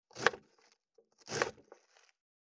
529切る,包丁,厨房,台所,野菜切る,咀嚼音,ナイフ,調理音,
効果音厨房/台所/レストラン/kitchen食器食材